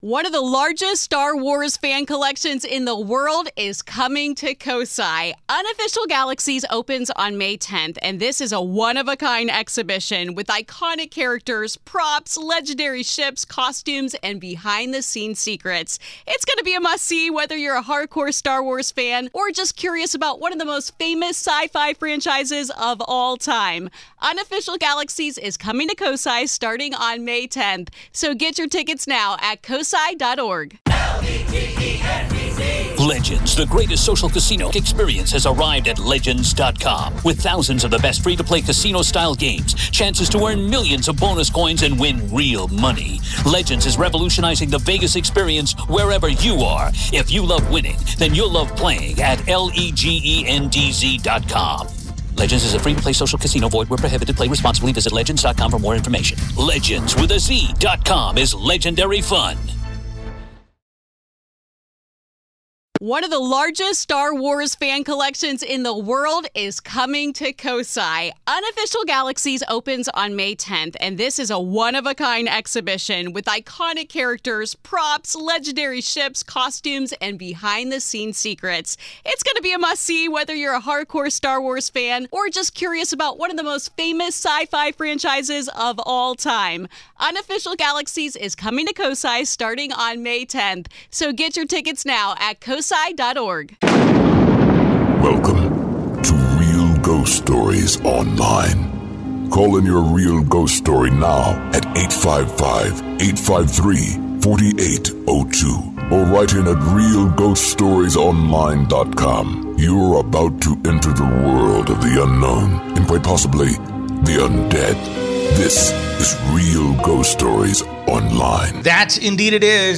Listeners call in their real ghost stories and share haunting talkes of the dead coming back to life!